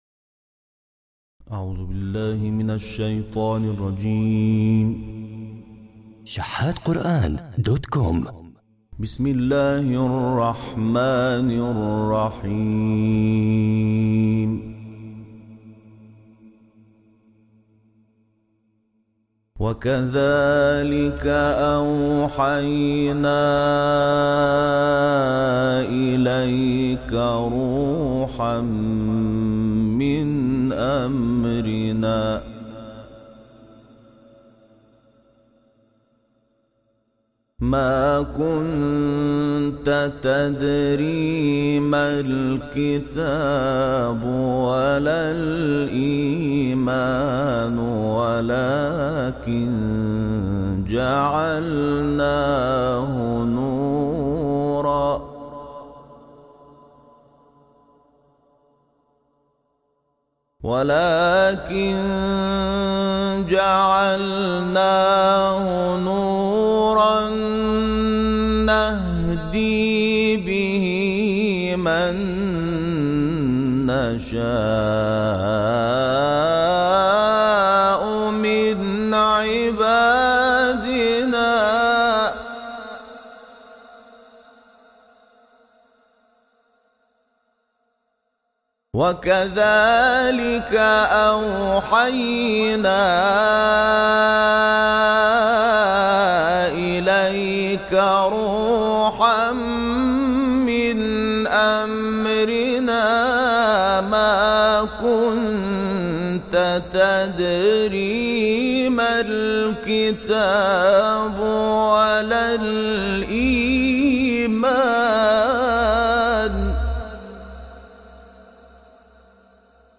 تلاوت‌های استودیویی از «محمود شحات»
گروه شبکه اجتماعی: تلاوت آیاتی از سوره‌های مبارکه الرحمن، شوری و ضحی را که در استودیو توسط محمود شحات انور ضبط شده است، مشاهده می‌کنید.
به گزارش خبرگزاری بین المللی قرآن(ایکنا)، محمود شحات انور، قاری ممتاز مصری، طی سفری که به آفریقای جنوبی داشت، تلاوت‌های استودیویی را در این کشور ضبط کرده است که در کانال تلگرامی این قاری منتشر شد.